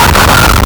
Player_Glitch [22].wav